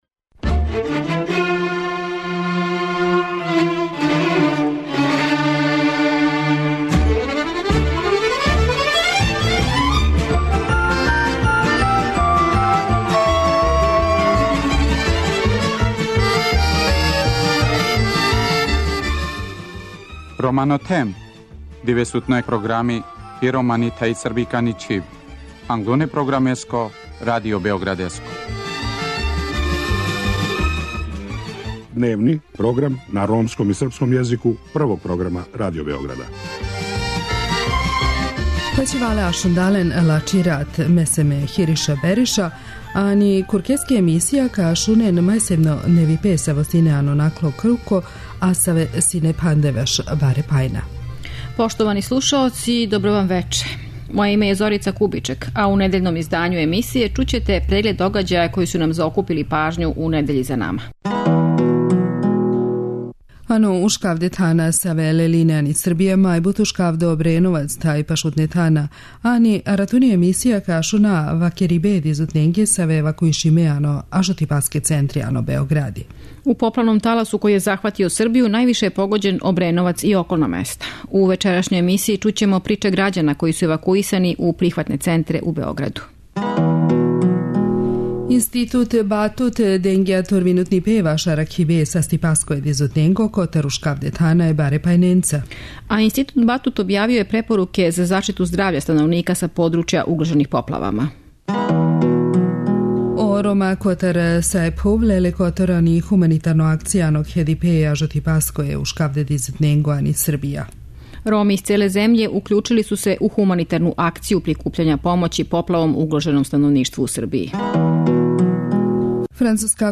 У поплавном таласу који је захватио Србију највише је погодођен Обреновац и околна места. У вечерашњој емисији чућемо приче грађана који су евакуисани у прихватне центре у Београду.